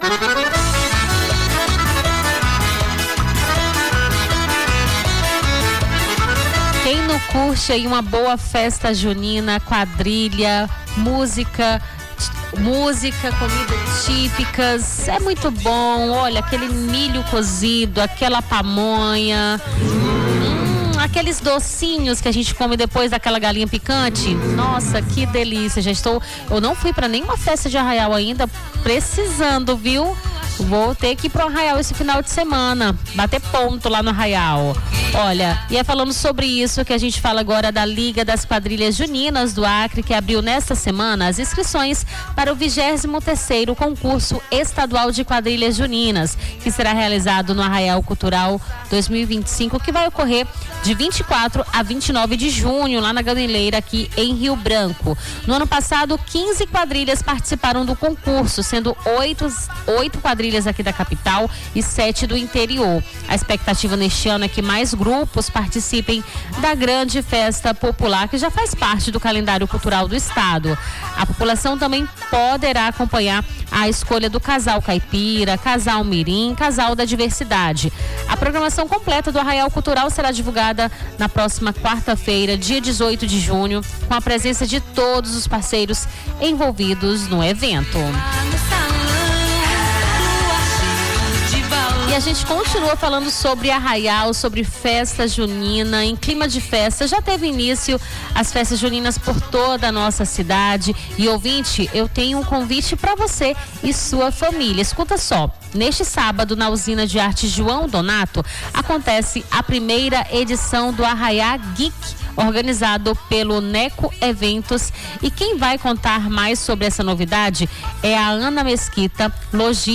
Nome do Artista - CENSURA - ENTREVISTA (ARRAIÁ GEEK) 13-06-25.mp3